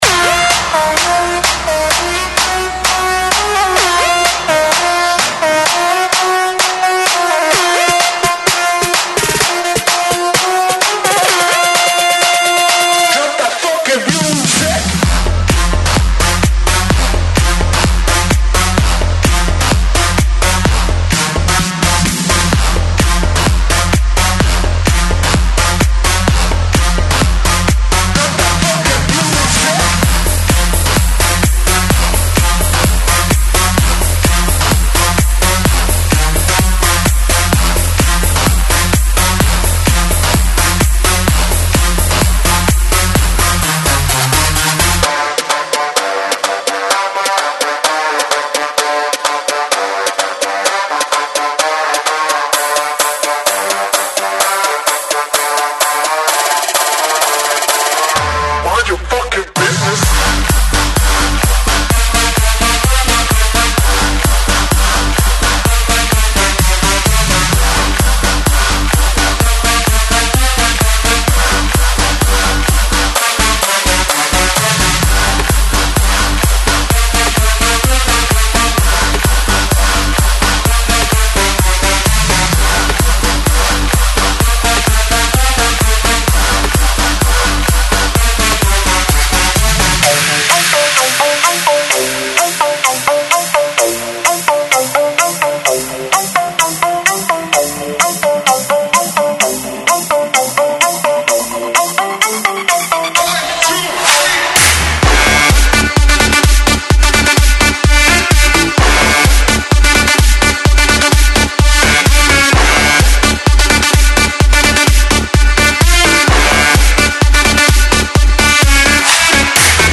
1- 五个 128bpm 的音乐制作套件；